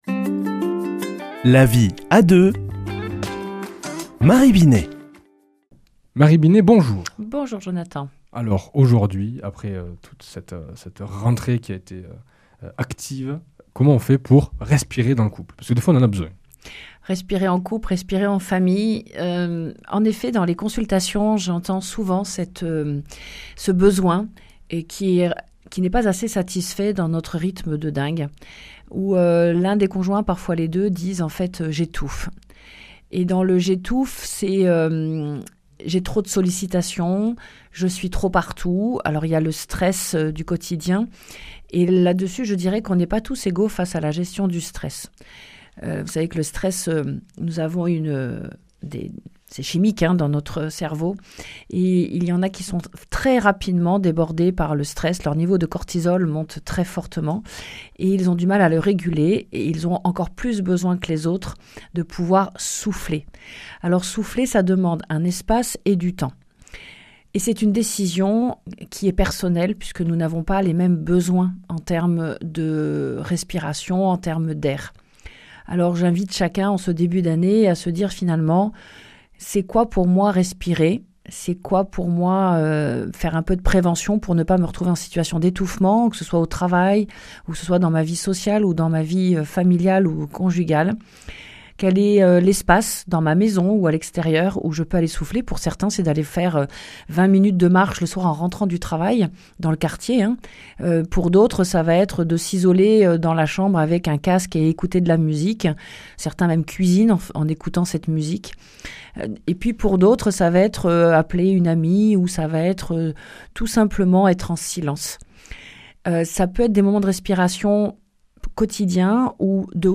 mardi 16 septembre 2025 Chronique La vie à deux Durée 4 min